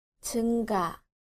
• 증가
• jeungga